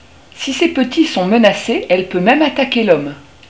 Le cri de la hulotte